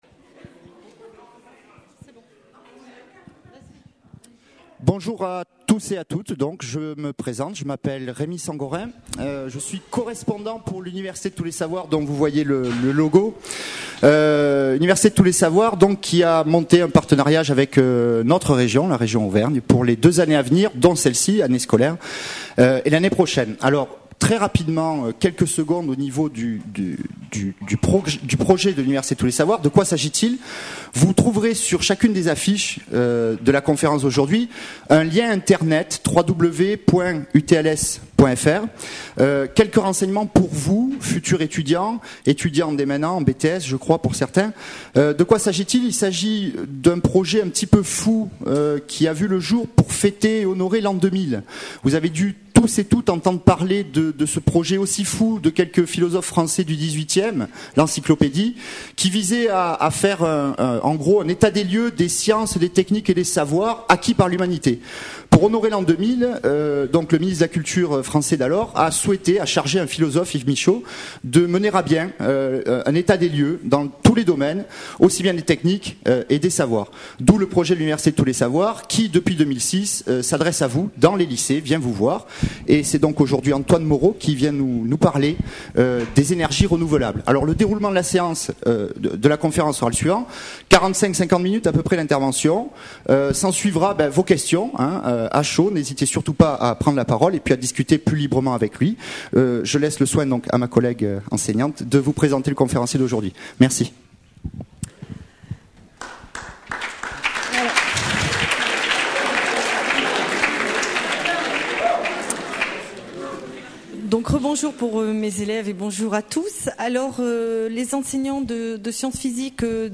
Une conférence de l'UTLS au lycée Les énergies renouvelables